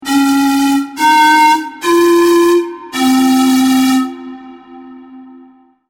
Poniżej zamieszczono przykładowe dźwięki otrzymane przy pomocy modelu quasi-fizycznego.
efekt wibrato